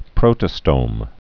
(prōttə-stōm)